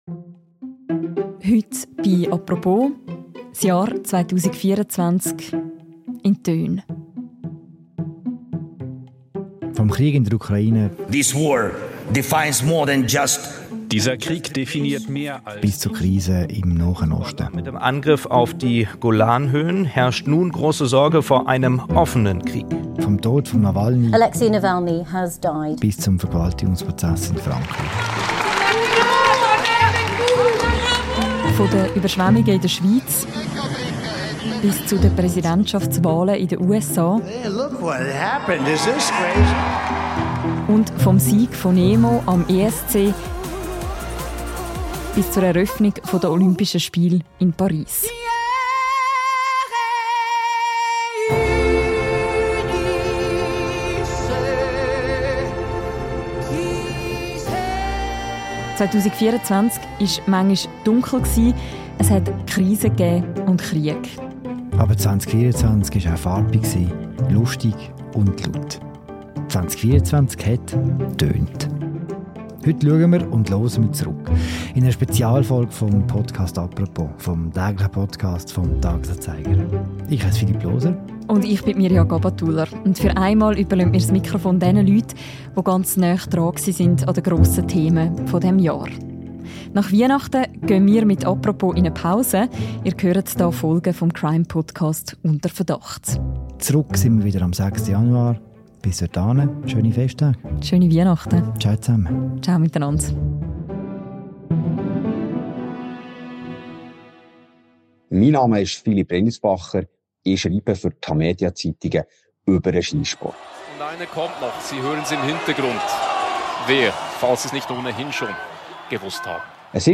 Ein Jahresrückblick in Spezialform: Das Jahr 2024 war mal laut, mal schrill, mal mitreisend. In Erinnerung bleiben die Stimmen von prägenden Politikerinnen und Politiker, der Jubel an Sportevents sowie eindringliche Zitate.
In einer neuen Spezialfolge des täglichen Podcasts «Apropos» schauen und hören wir zurück auf das Jahr – mit den Redaktorinnen und Redaktoren, die nahe dran waren an den grossen Themen und Geschehnissen des Jahres.